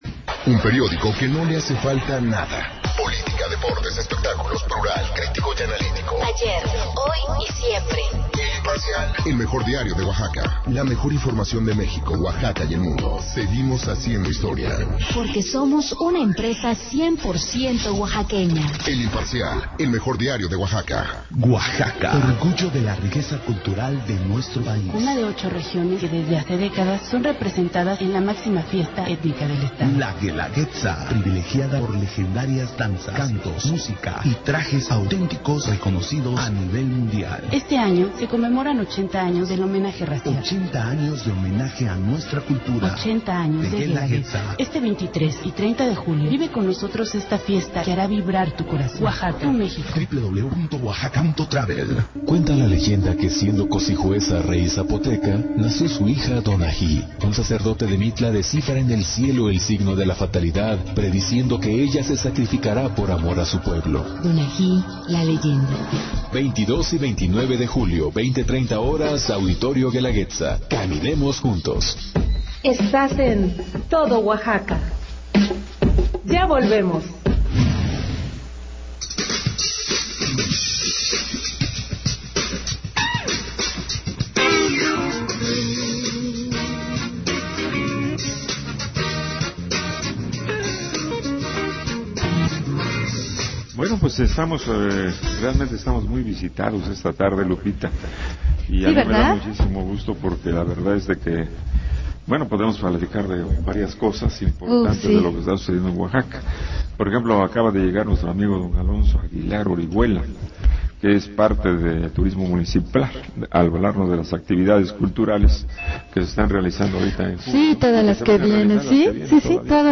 El Presidente Municipal de Huajuapan de León, Francisco Círigo, vía telefónica comentó sobre la fiesta que viven estas dos semanas en aquella bella población, conmemorando el Bicentenario de la Toma de Huajuapan.